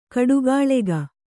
♪ kaḍugāḷega